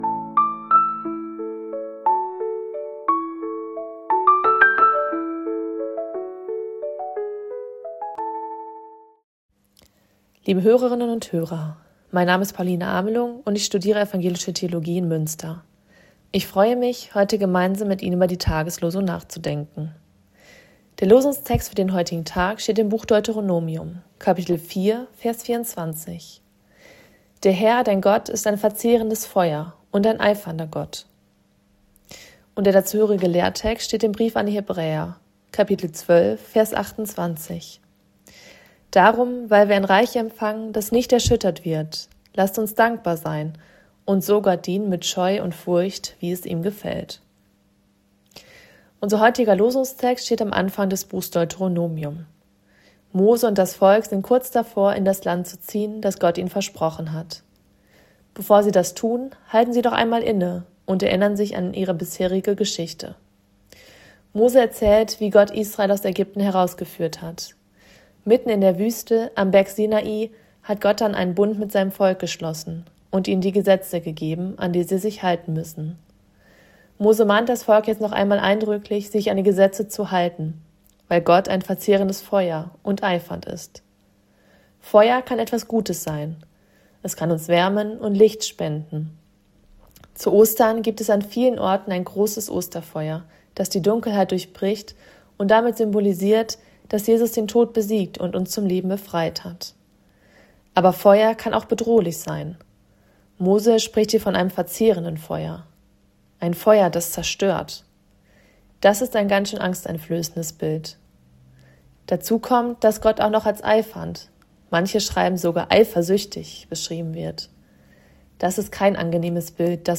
Losungsandacht für Samstag, 18.04.2026